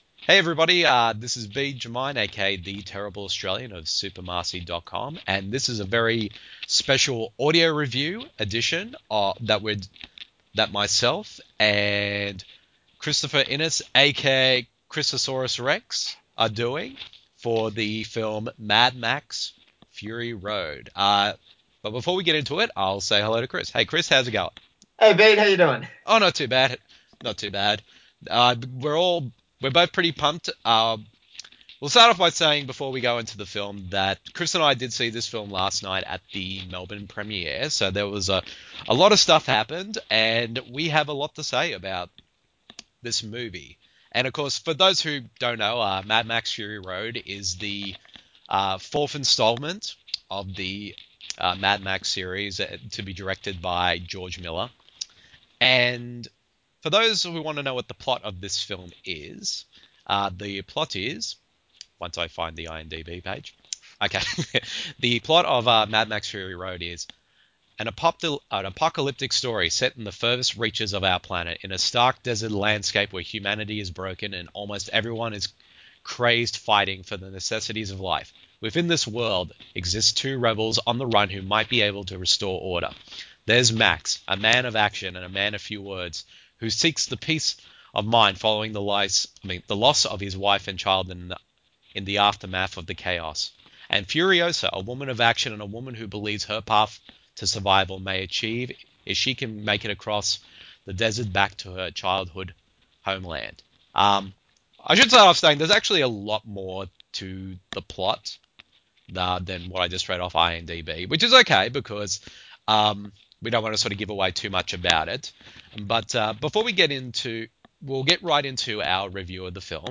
[Audio Review] Mad Max: Fury Road (2015)
The following review in an audio format, as a back and forth discussion between the two of us.